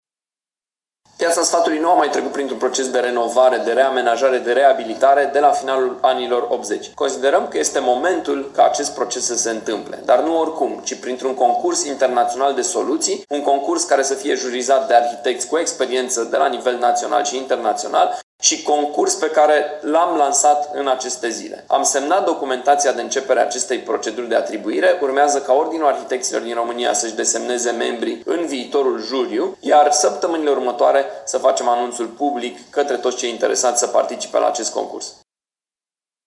Primarul Allen Coliban: